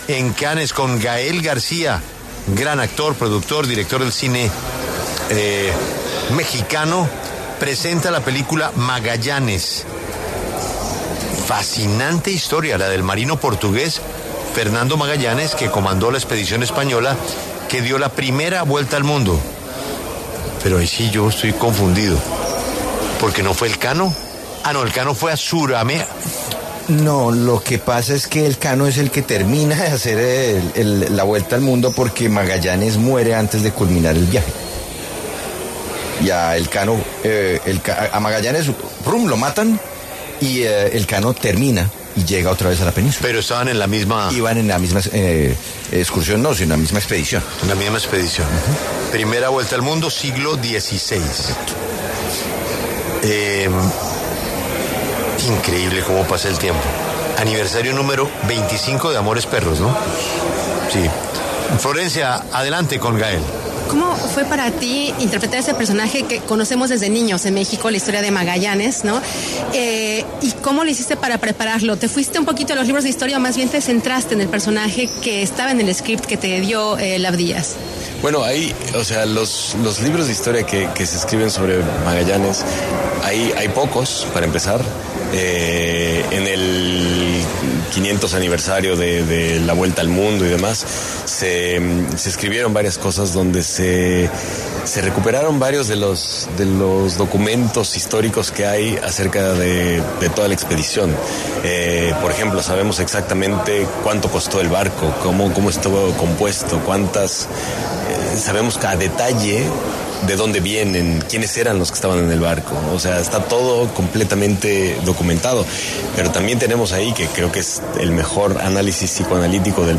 La W conversó con Gael García, actor que interpretó a Magallanes en la película que muestra detalles históricos precisos de la vuelta al mundo.
Gael García Bernal, actor, director y productor de cine mexicano, pasó por los micrófonos de La W, con Julio Sánchez Cristo, para hablar sobre su película ‘Magallanes’, la cual es presentada en el Festival de Cannes 2025.